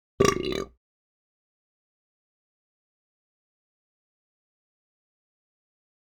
Burp.wav